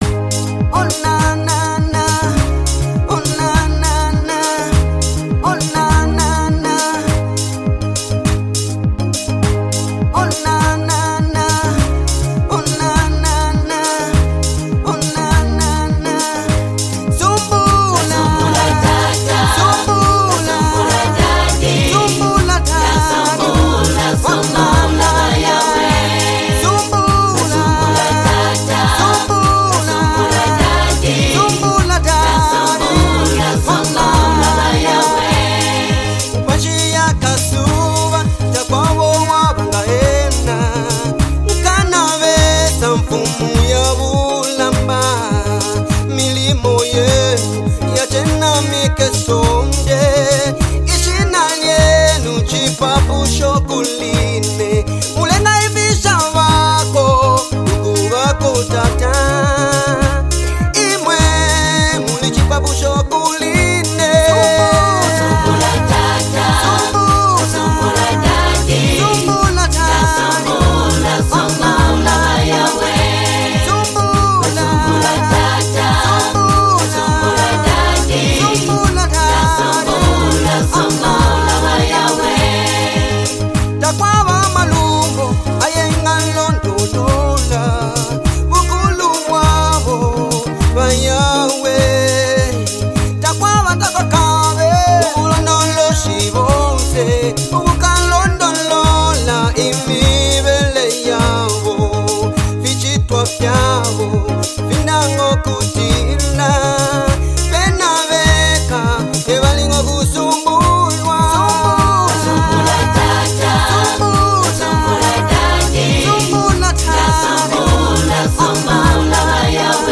soul-stirring anthem
contemporary sounds